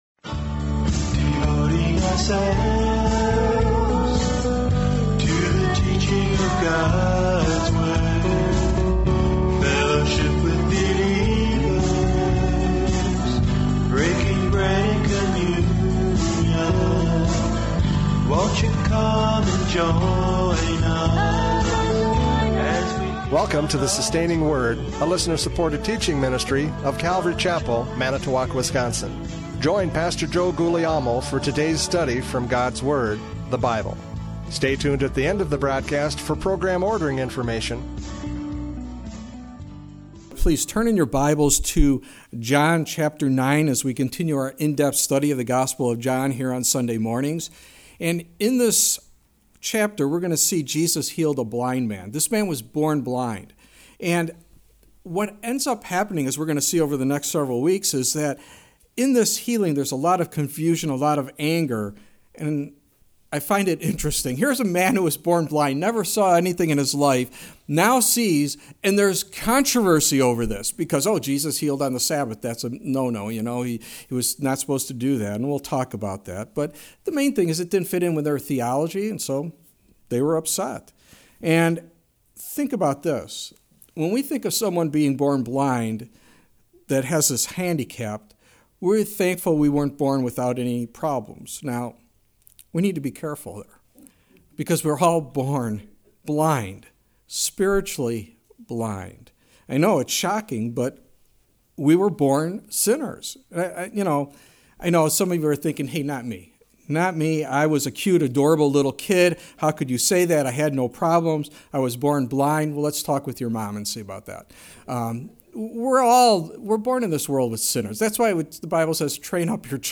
John 9:1-7 Service Type: Radio Programs « John 8:39-59 Physical or Spiritual Birth?